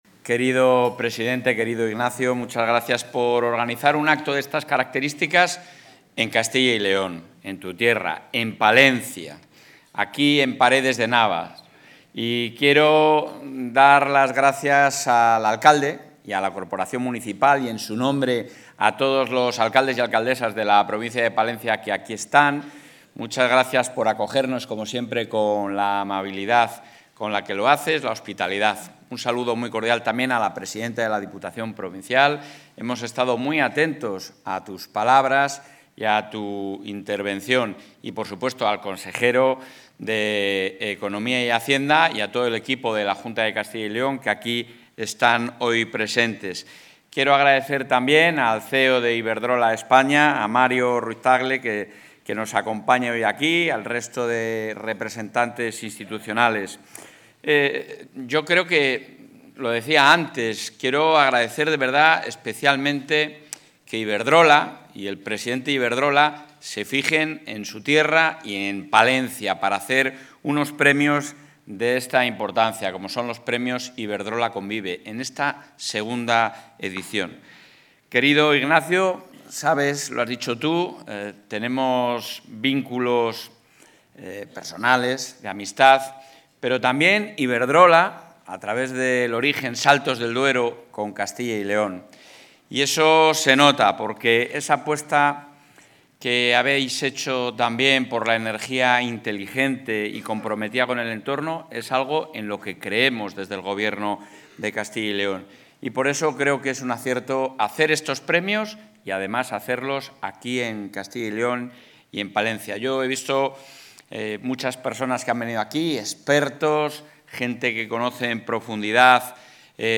El presidente de la Junta de Castilla y León, Alfonso Fernández Mañueco, ha participado hoy en el municipio palentino de Paredes...
Intervención del presidente.